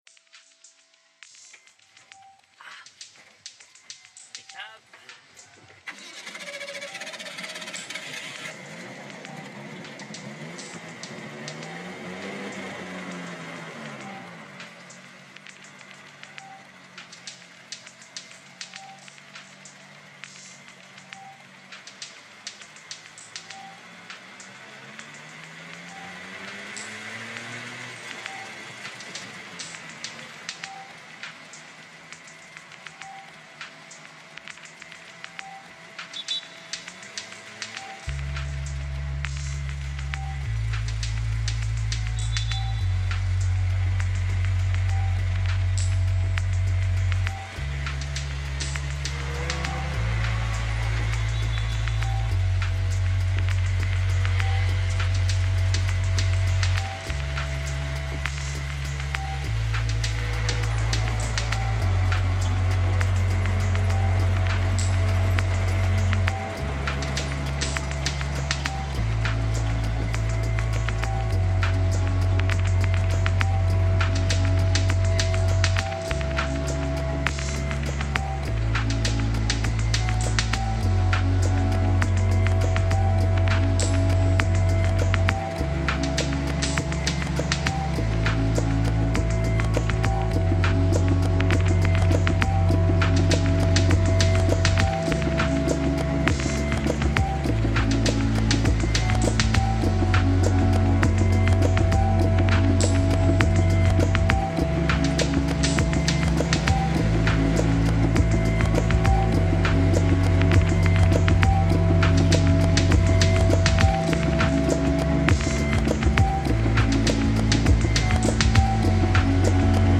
Tuk tuk ride in Bangkok reimagined